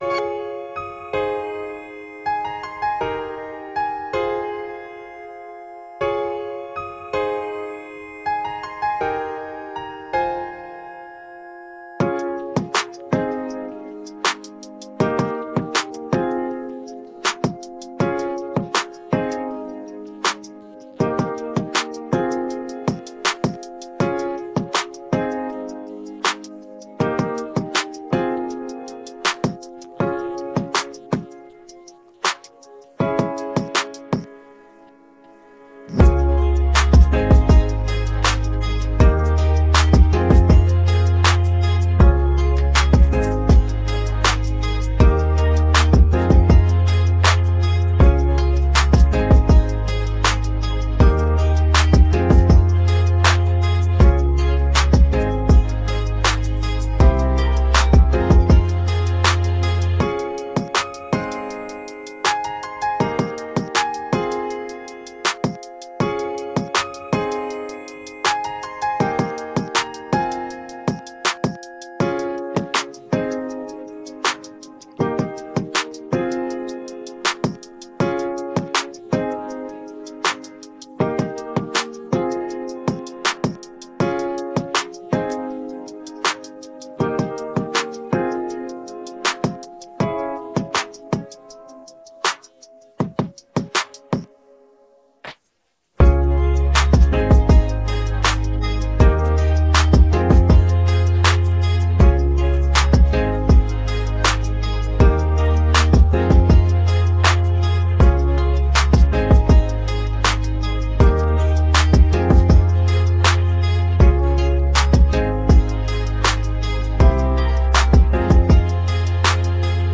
instrumental
) komplett ignoriert. eine eiernde ode an die freude?